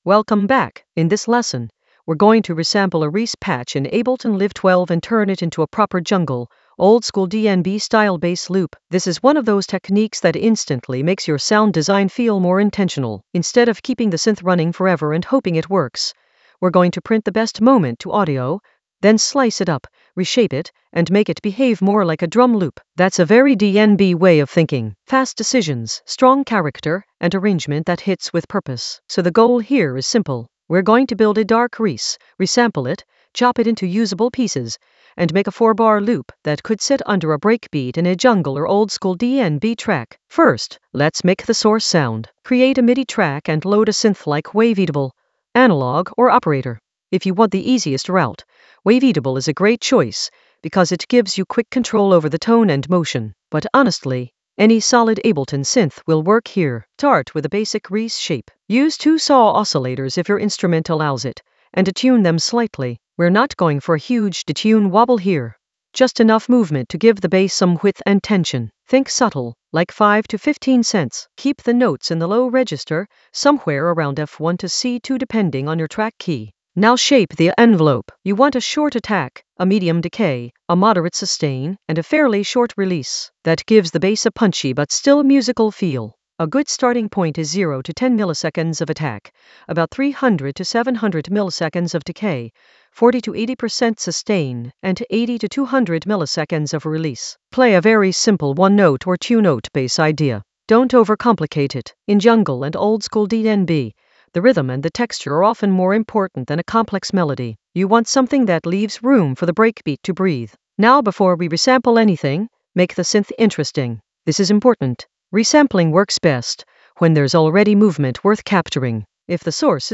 An AI-generated beginner Ableton lesson focused on Resample a reese patch in Ableton Live 12 for jungle oldskool DnB vibes in the Resampling area of drum and bass production.
Narrated lesson audio
The voice track includes the tutorial plus extra teacher commentary.